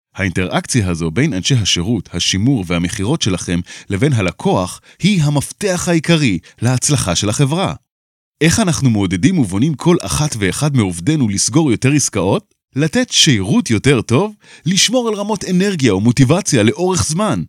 למשל לסרטון פרסומי אני ממליץ על הקריין